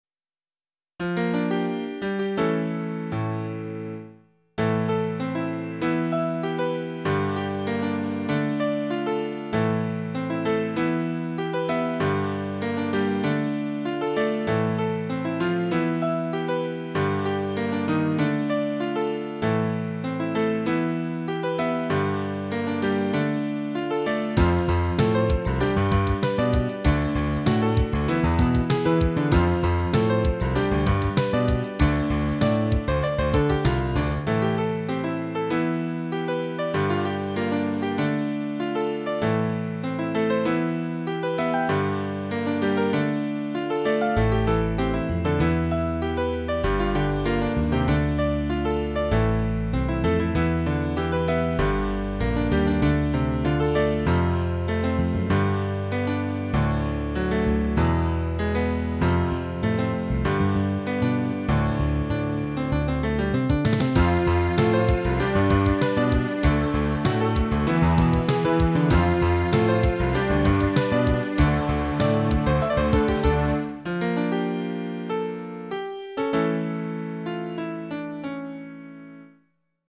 A piano trio.